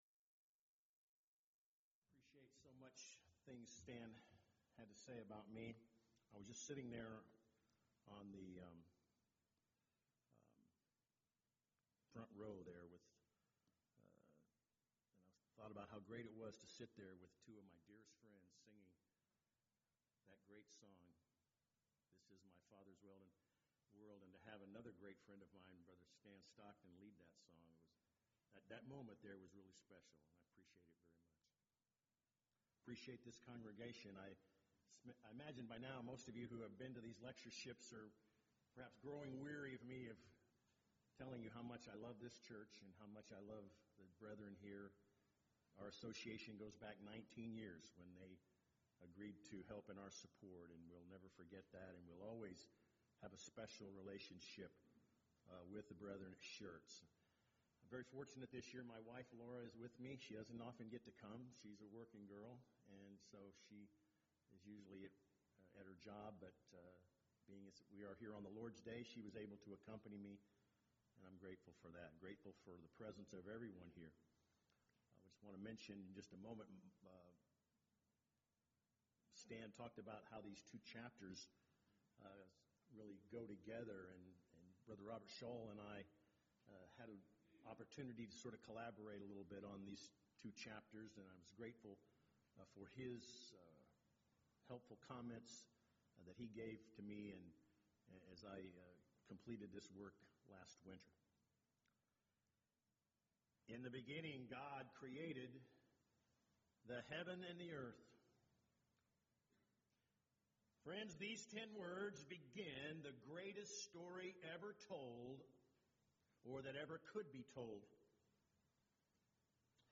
Event: 16th Annual Schertz Lectures Theme/Title: Studies in Genesis